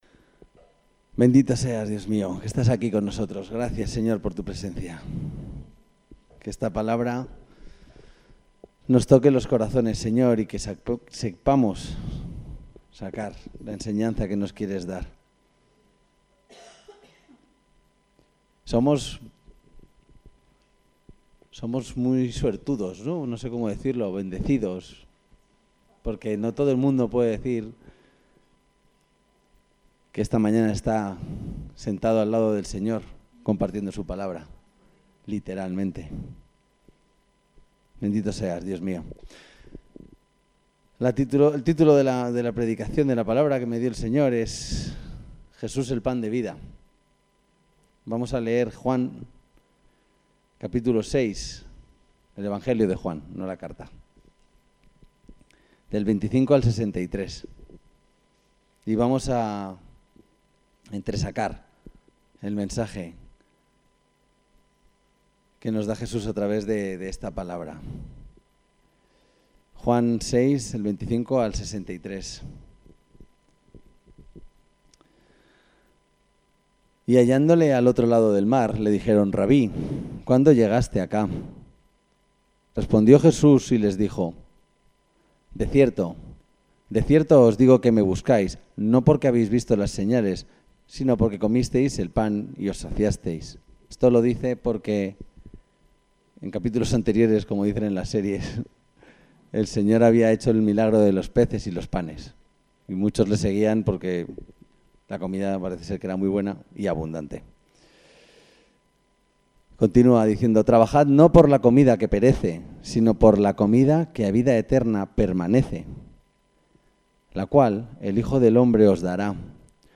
El texto de la predicación Jesús pan de vida